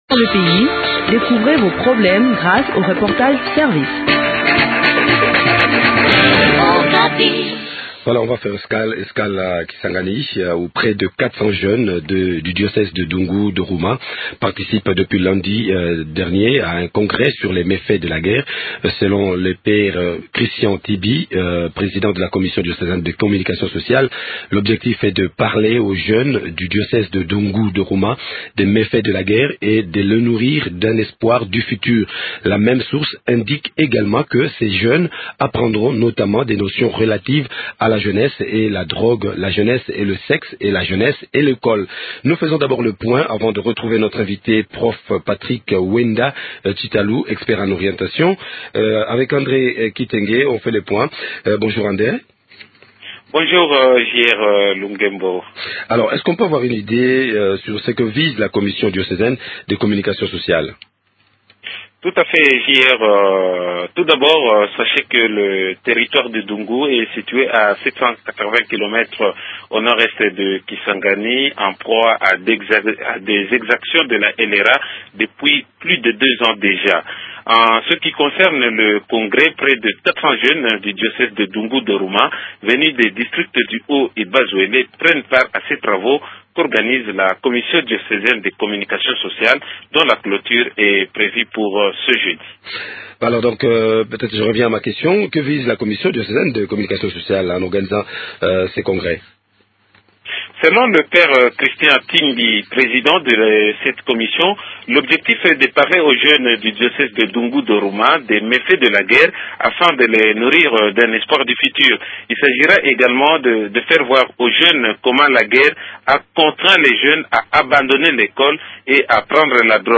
Le point sur le déroulement de ces travaux dans cet entretien